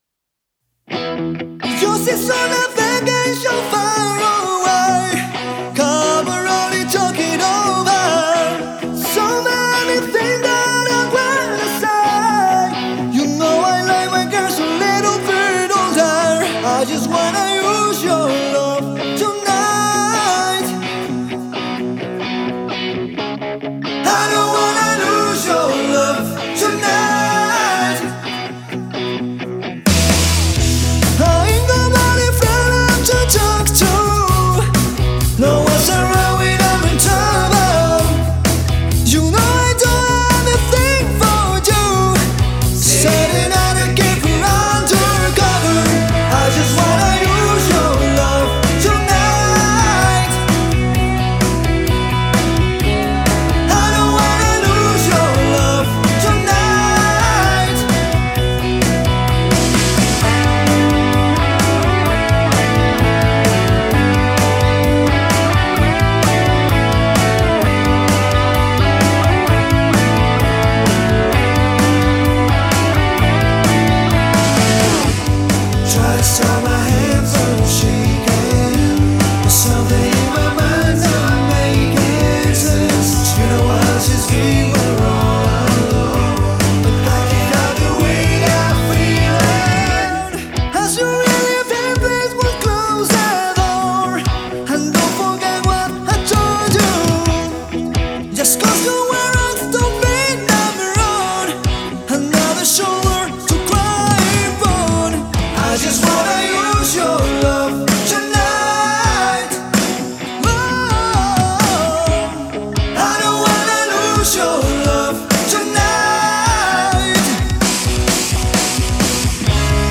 tuvo lugar en febrero de 2024 en el estudio de grabación